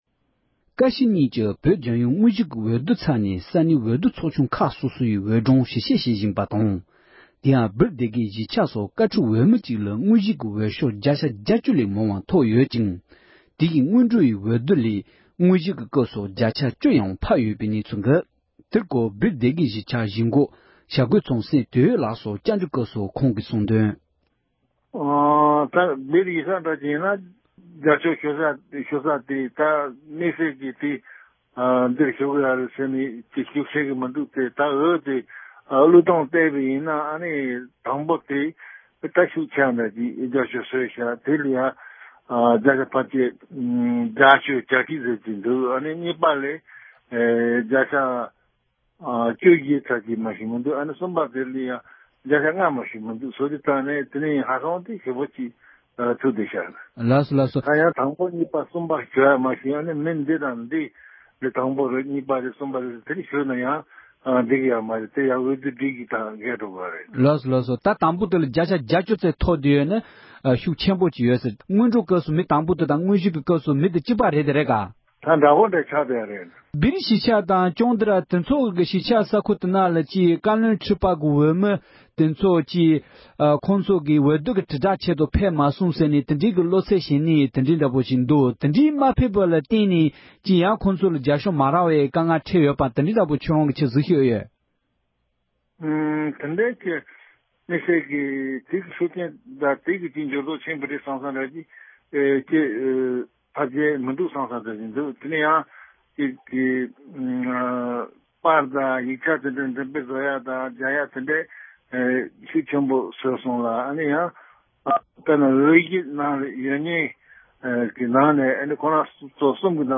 བཅའ་འདྲི་ཞུས་པ་ཞིག